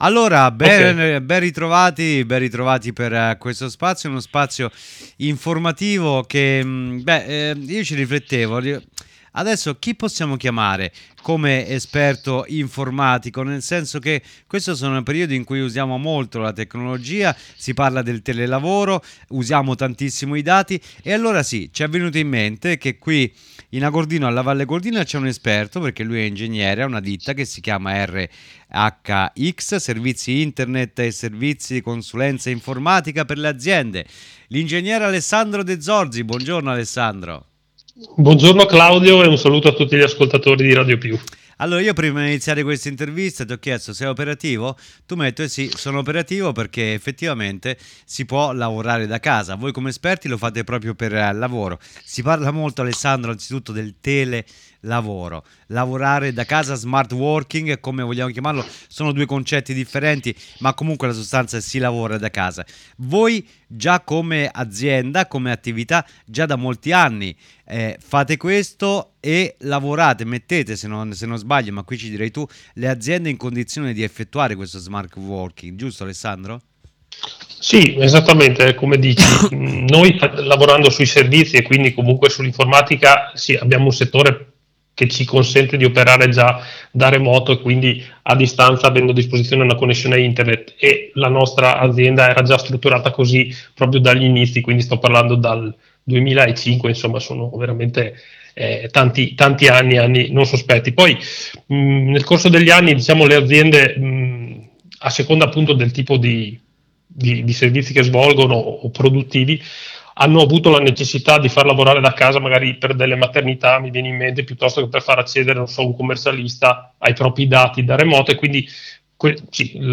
Ospite di Radio Più